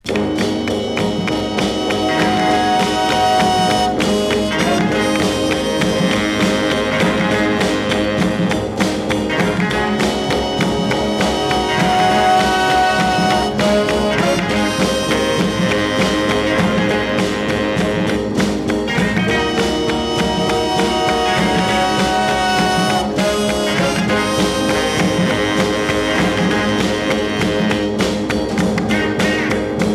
Groove easy listening